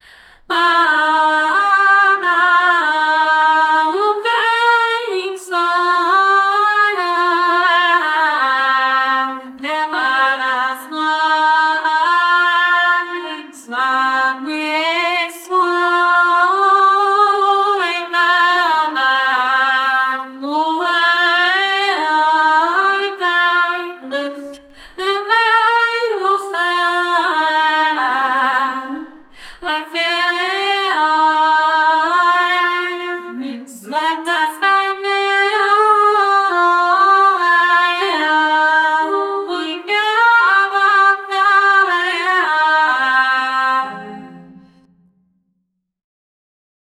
An a cappella of a beautiful female voice with jazz style singing from the 50s, 60s,70s, 80s,90s, in English with an American accent 0:47 Created Apr 13, 2025 4:28 PM greek choir, abstract gibberish, dramatic, suspended, a cappella, no musical instruments 0:10 Created Jun 13, 2025 1:45 AM
an-a-cappella-of-a-kg7pukwr.wav